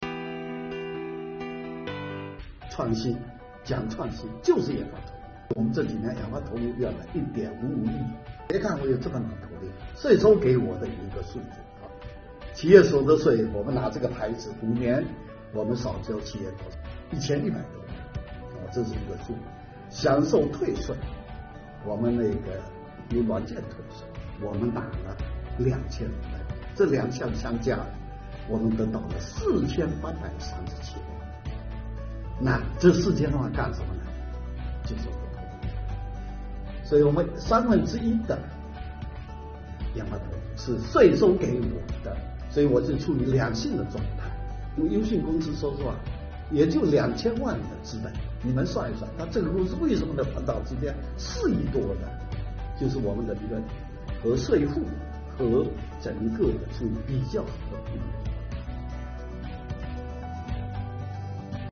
2月15日下午，记者来到厦门优迅高速芯片有限公司采访。